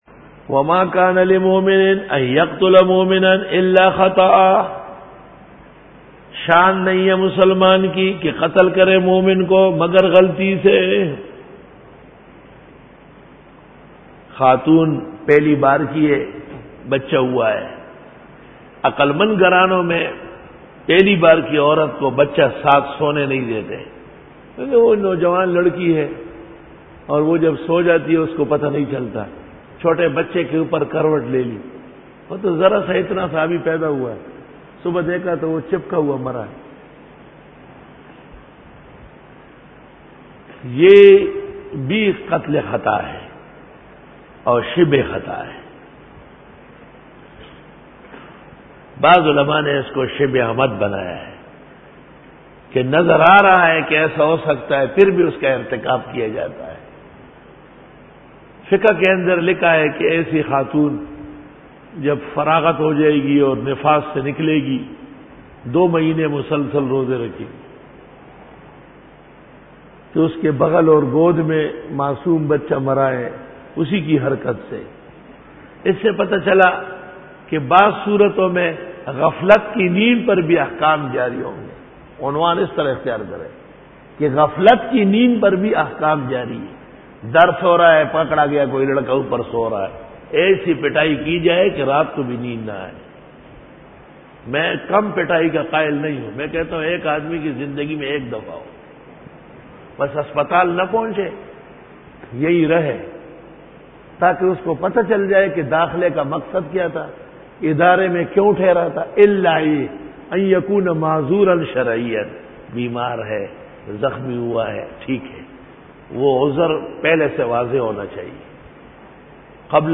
سورۃ النساء-رکوع-13 Bayan